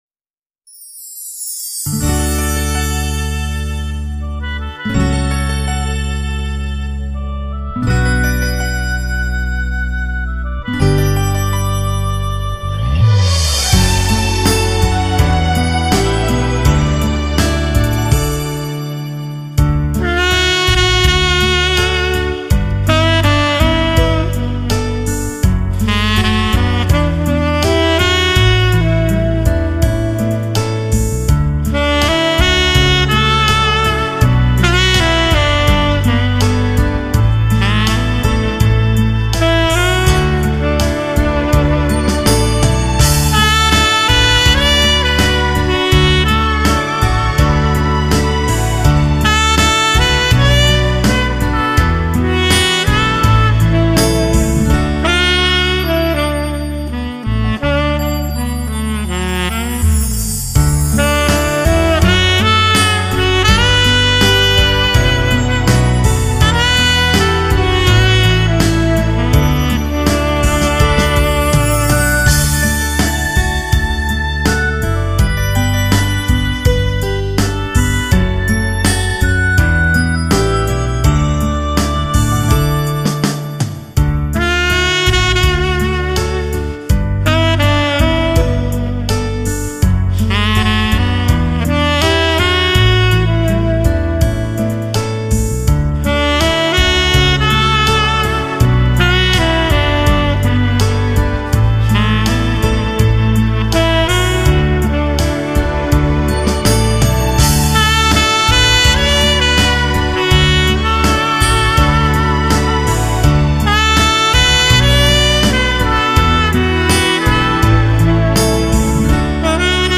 极富视听效果的发烧靓声，堪称发烧器材